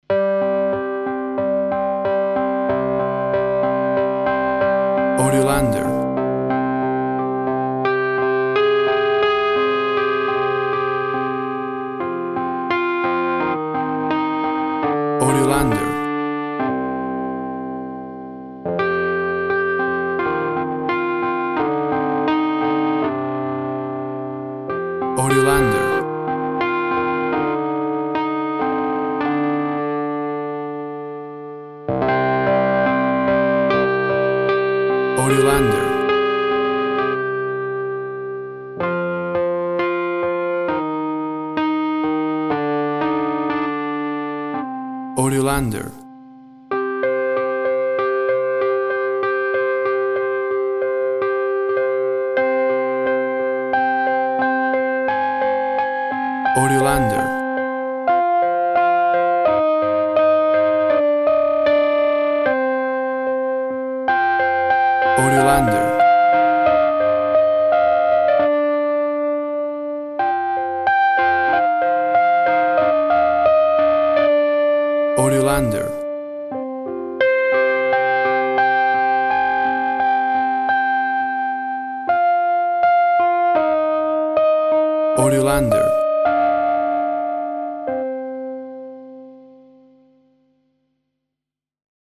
Scary children music.
WAV Sample Rate 16-Bit Stereo, 44.1 kHz